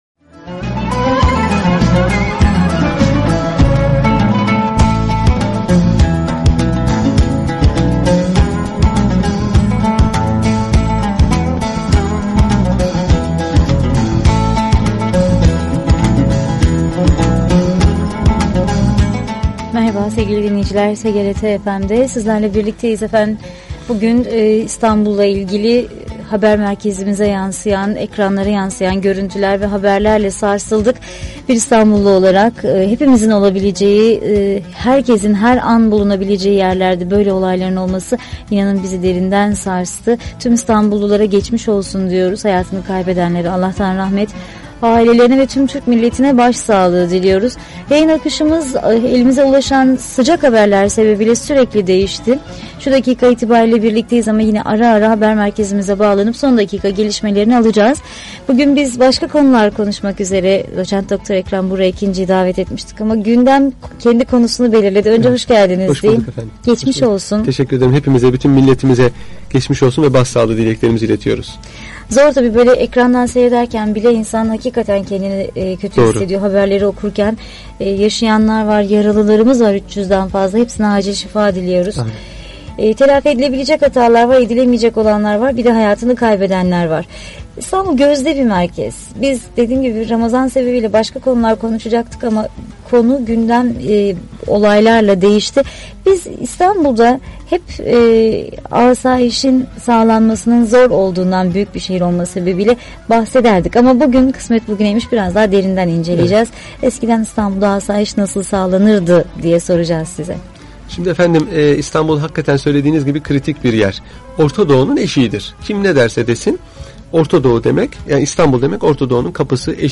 Radyo Programi - Eski İstanbul'da Asayiş